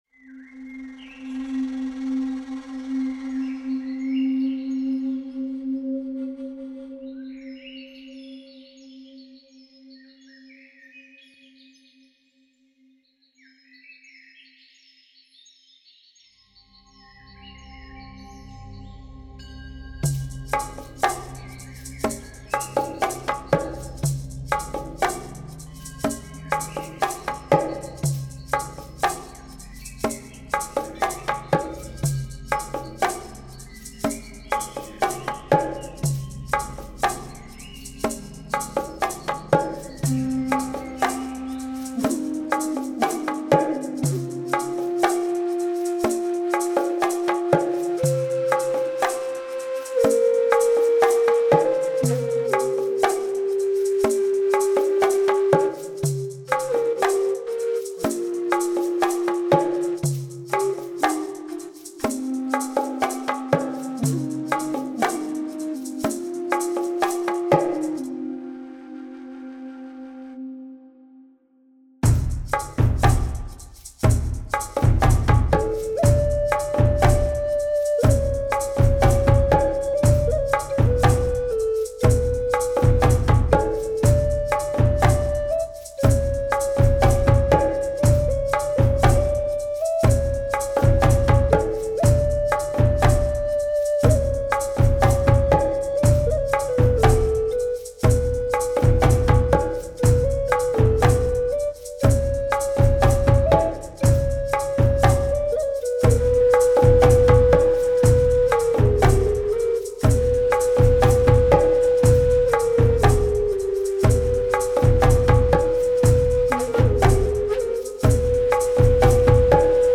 Keyboards, Acoustic and Electric Guitars and Programming